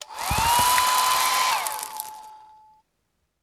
hand mining
drytool.wav